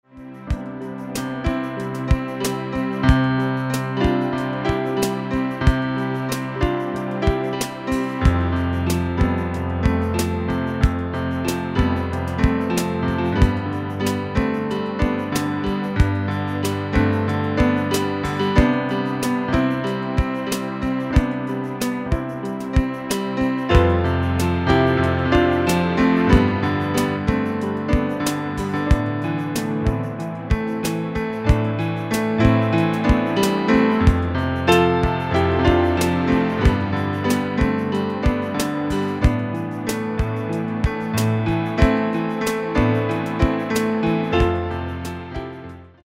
Instrumental (guitar piano)